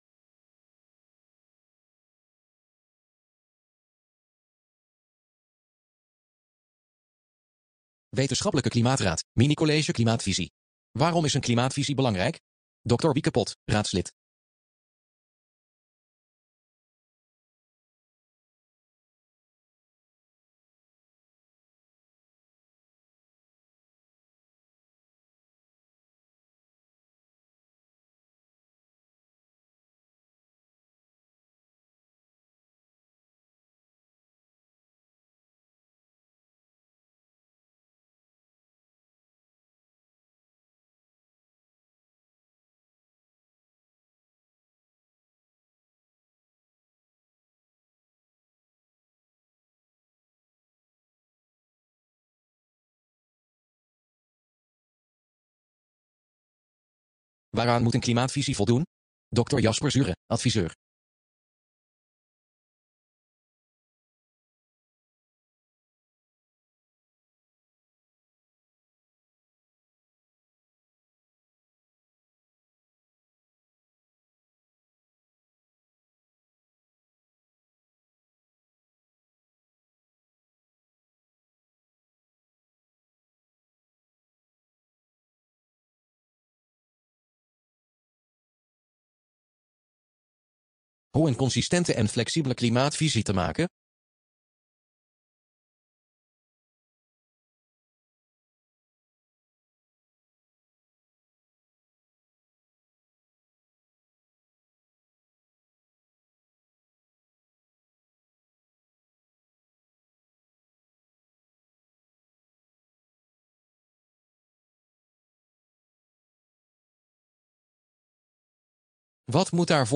WKR Minicollege Klimaatvisie